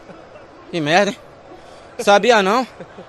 Jogador de futebol Marinho fica espantado quando descobre, pelo repórter, que levou terceiro cartão amarelo e diz: "que merda, sabia não"